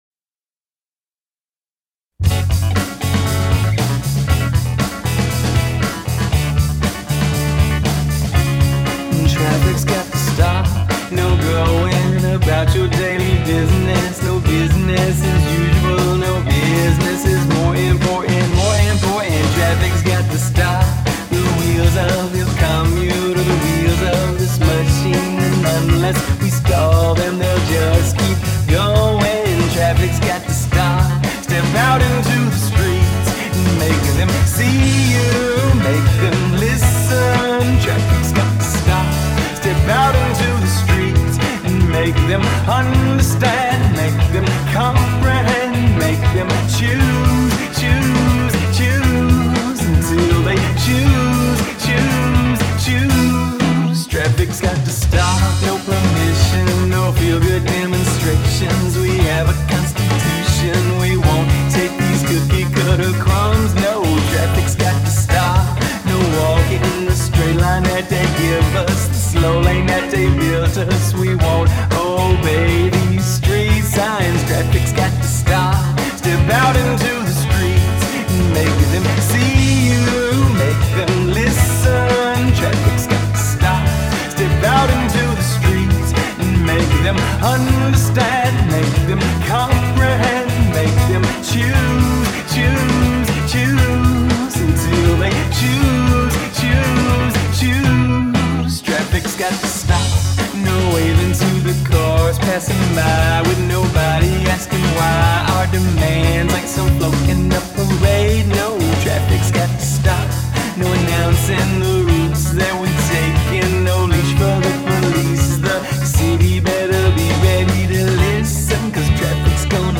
shrieking vocals